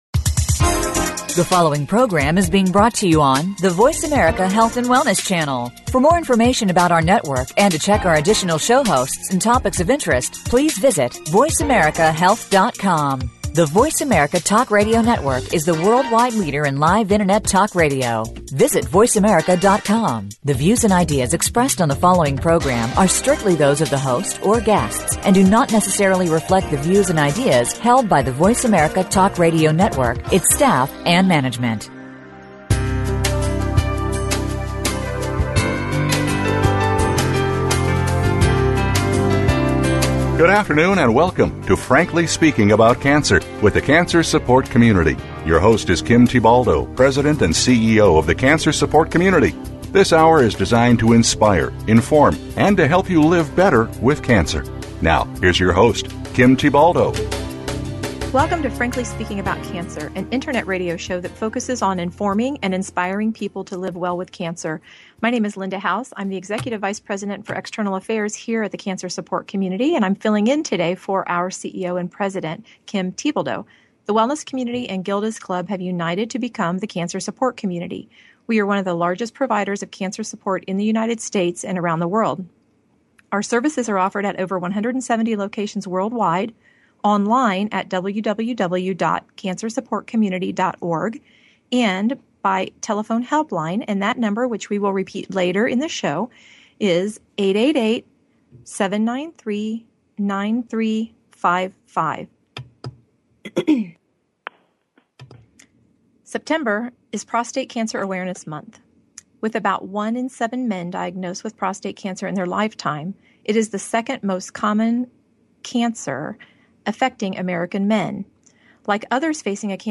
With one in seven men diagnosed with prostate cancer in their lifetimes, it is the second most common cancer affecting men in the U.S. This week’s radio show focuses on the unique emotional and social needs of men with prostate cancer, as well as challenges facing men who are seeking this support.